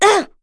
Laudia-Vox_Damage_03.wav